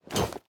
equip_iron4.ogg